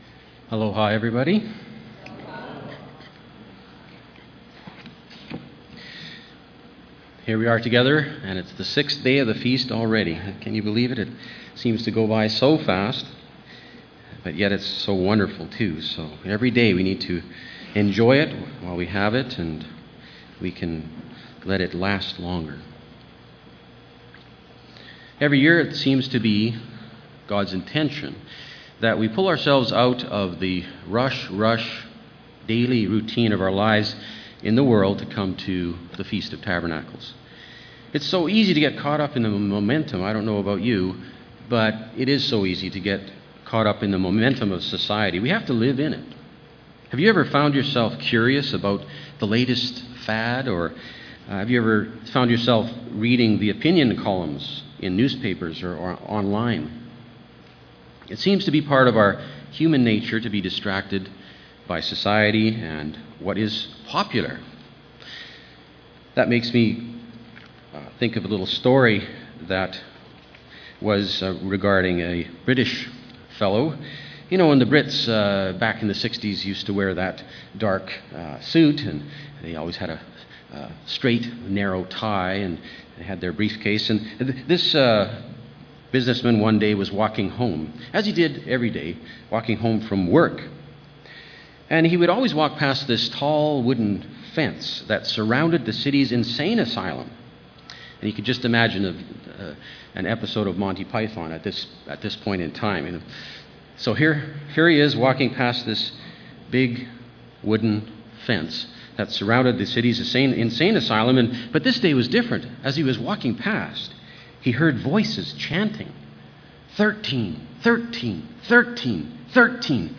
This sermon was given at the Maui, Hawaii 2013 Feast site.